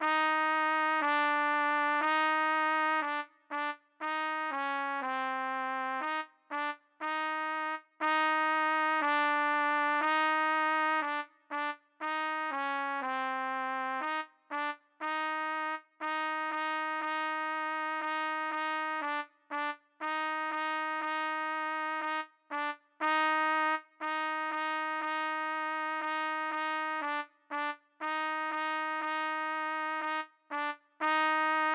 C=Harmony/Bass Part-for beginner players